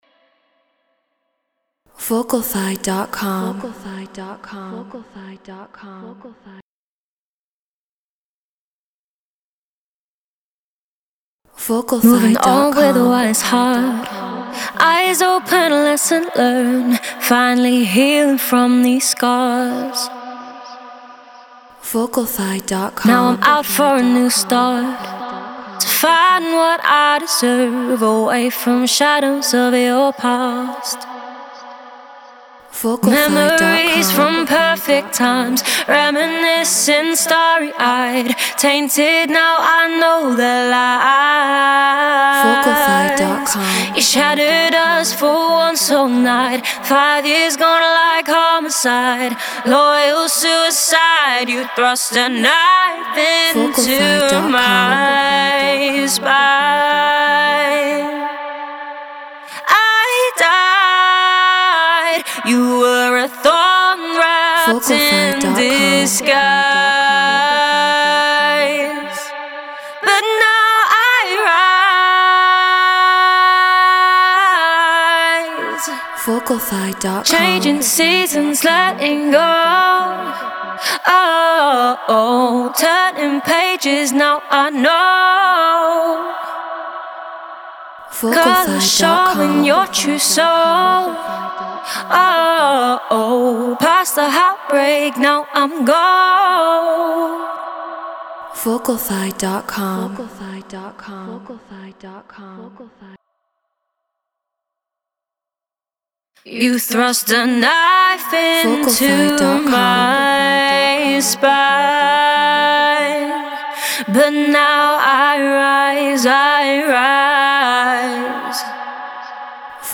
Drum & Bass 174 BPM Bmin